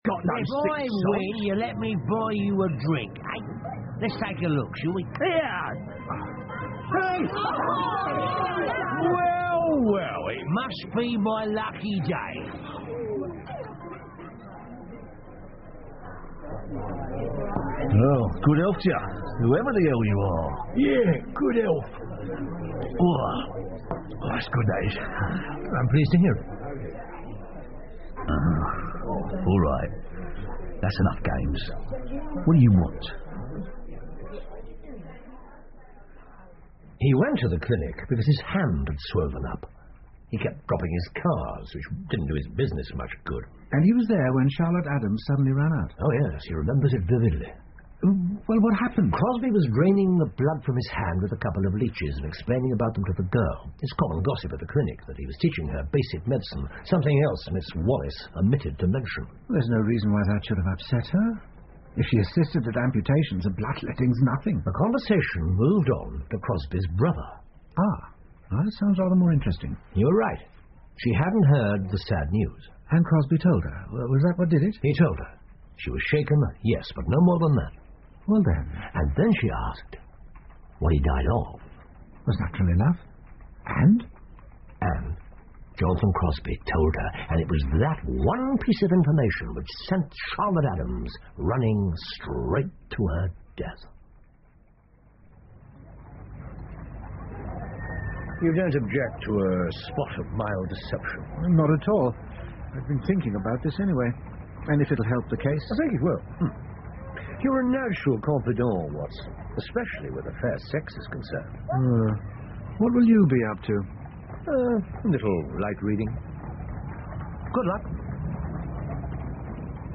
福尔摩斯广播剧 The Tragedy Of Hanbury Street 6 听力文件下载—在线英语听力室